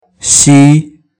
口音有声数据
口音（男声）